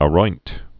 (ə-roint)